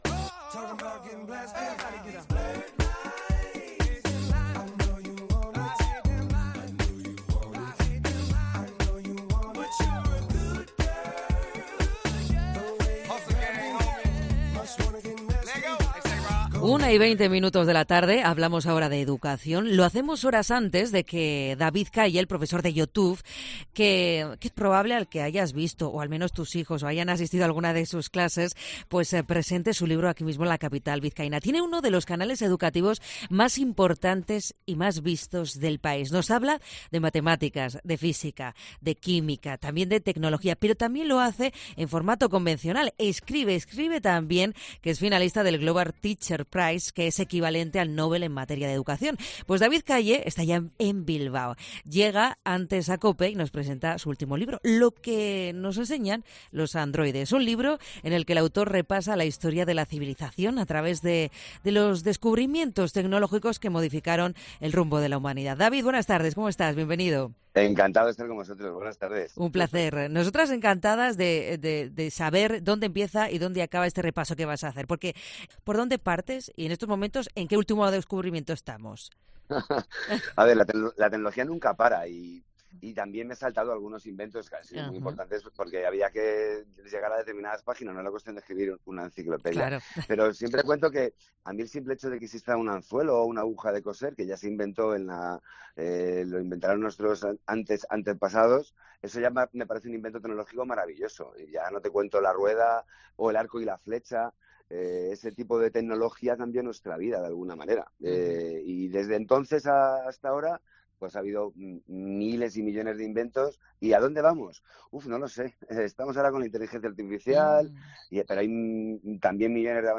Entrevista a David Calle, en COPE Euskadi